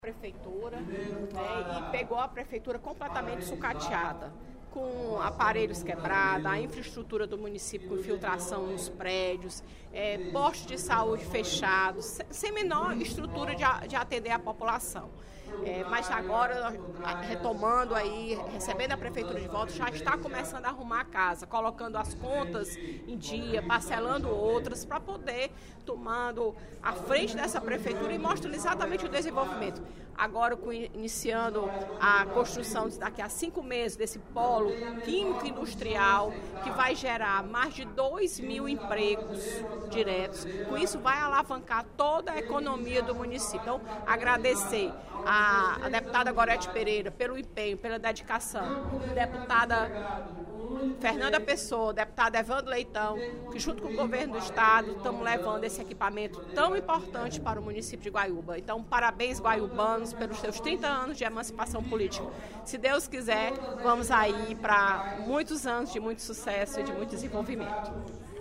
A deputada Fernanda Pessoa (PR) festejou, durante o primeiro expediente da sessão plenária da Assembleia Legislativa desta quinta-feira (16/03), os 30 anos de emancipação do município de Guaiúba.